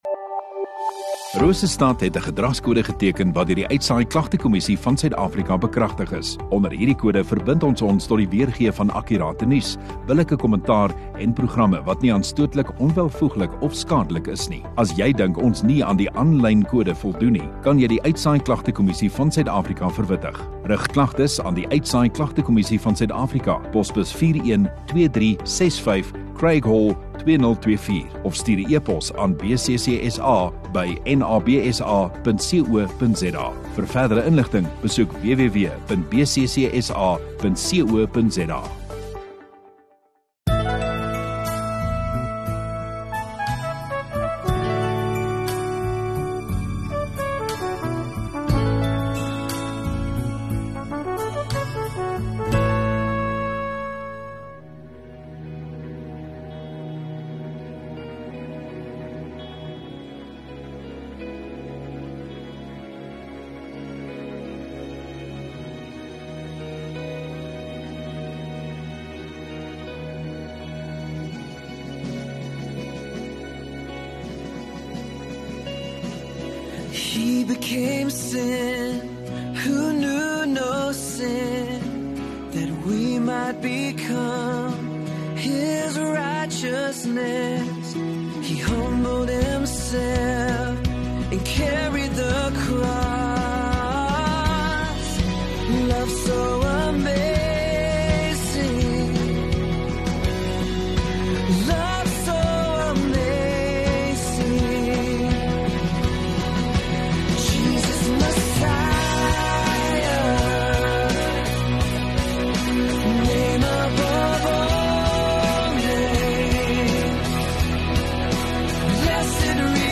30 Nov Sondagoggend Erediens